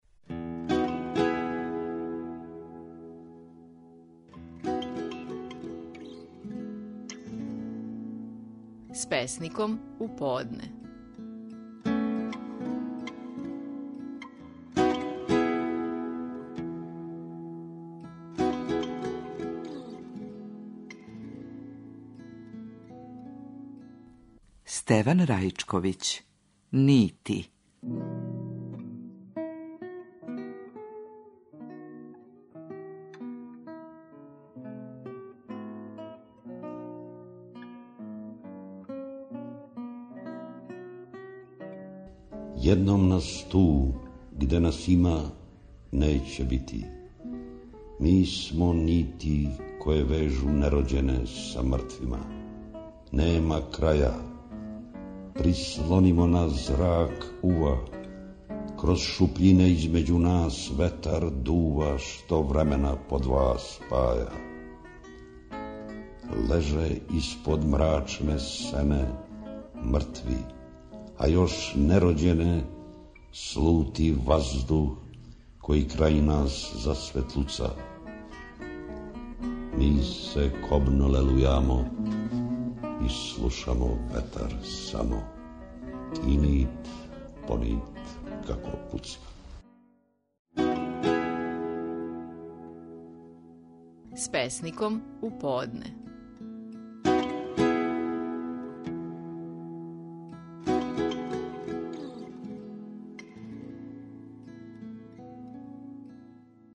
Стихови наших најпознатијих песника, у интерпретацији аутора.
Стеван Раичковић говори своју чувену песму "Нити".